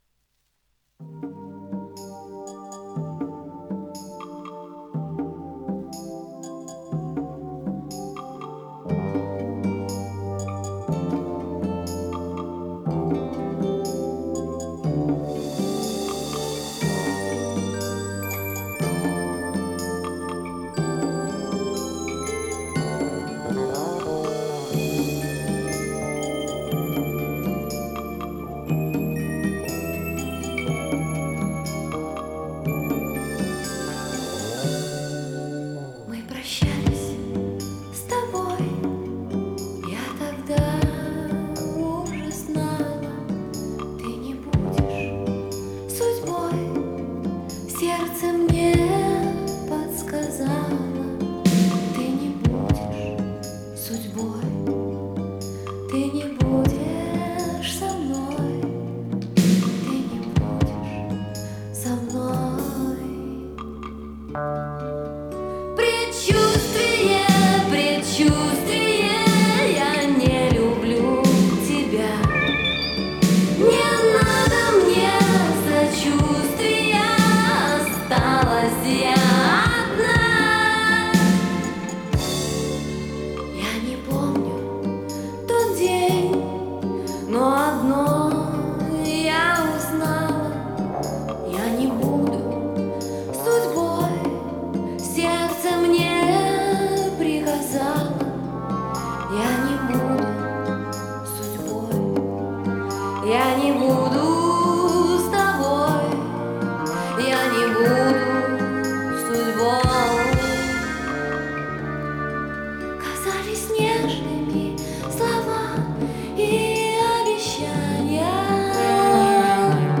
Жанр: Pop, Schlager